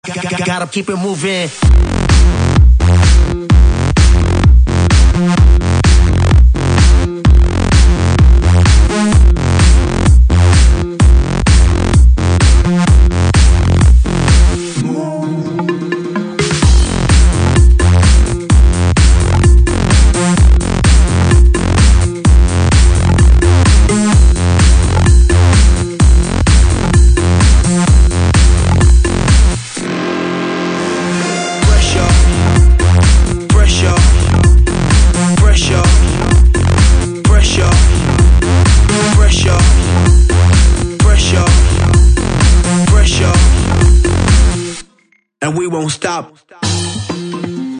/80kbps) Описание: Друзья!( Это Electro Mix.